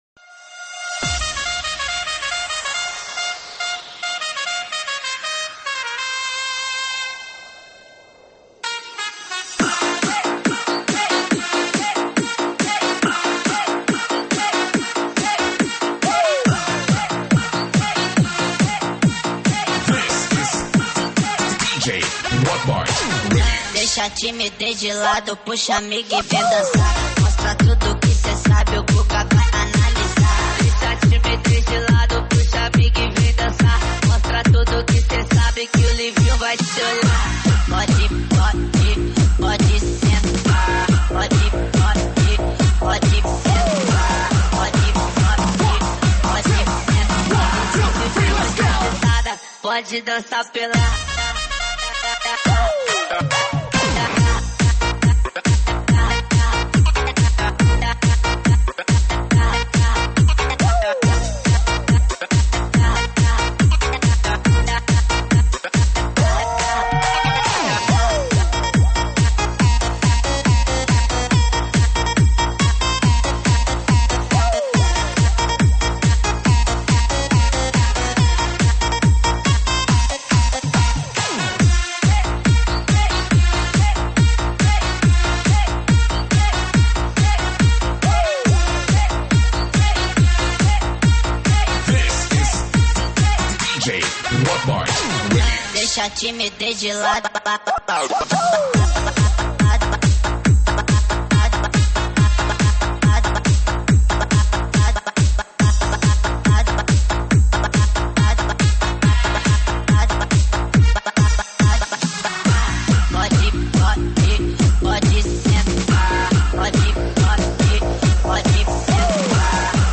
英文舞曲